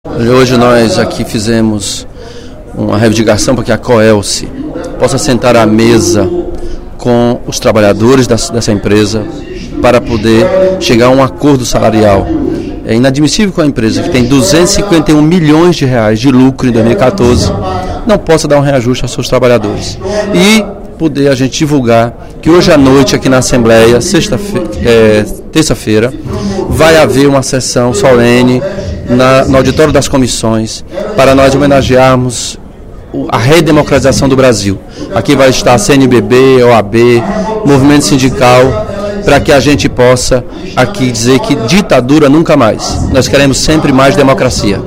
O deputado Elmano Freitas (PT), em pronunciamento no primeiro expediente da sessão plenária da Assembleia Legislativa desta terça-feira (31/03), pediu à Coelce que negocie com os funcionários, que se encontram em dissídio coletivo.